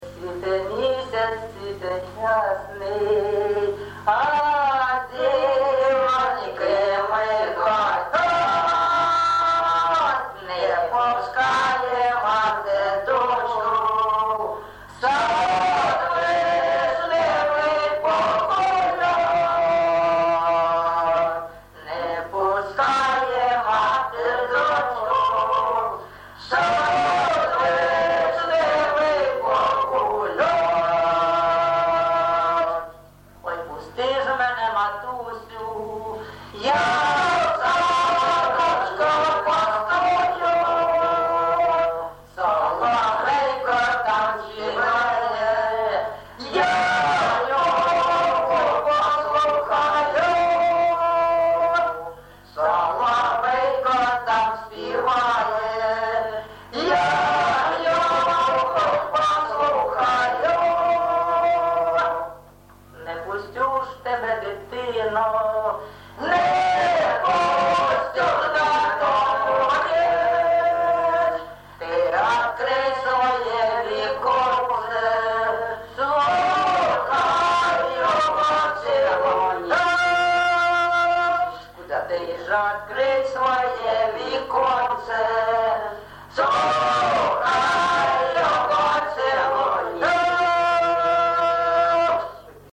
ЖанрПісні з особистого та родинного життя